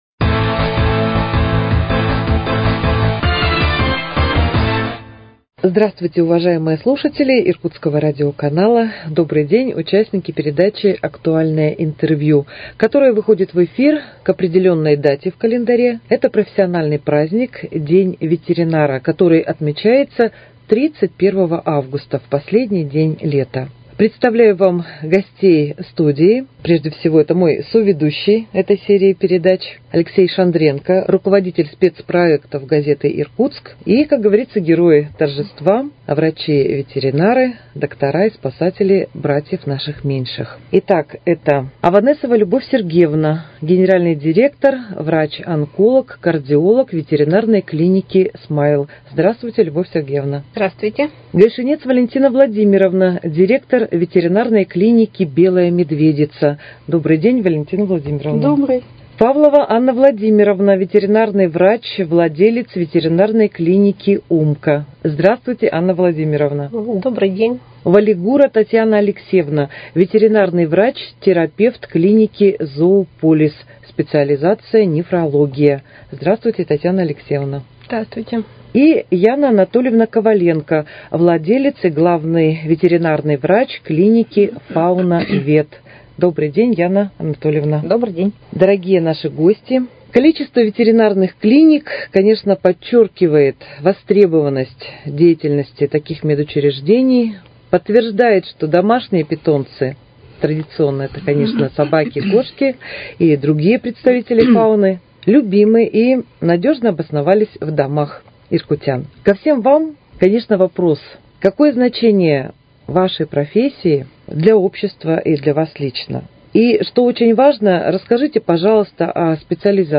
Актуальное интервью: Беседа с лучшими ветеринарами ко Дню ветеринарного работника